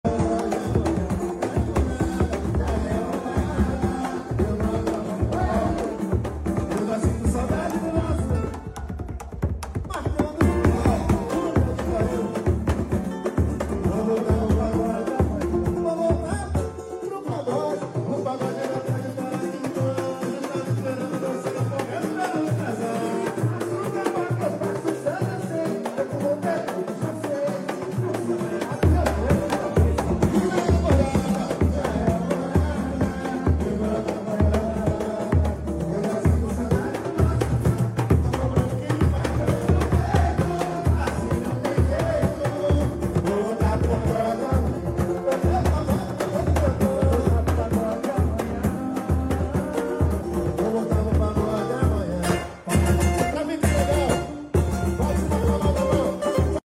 Aquela Virada De Surdo Que Sound Effects Free Download